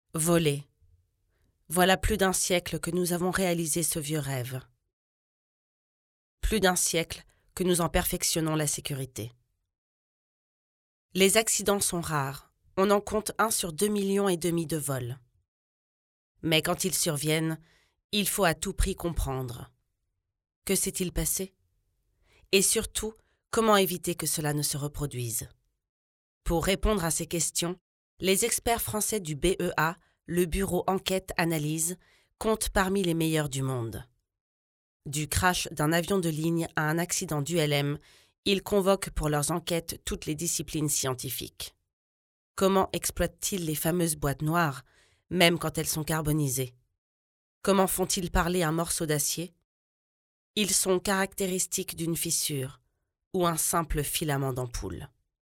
Voix off
Extrait Voix / Film Institutionnel
- Mezzo-soprano Soprano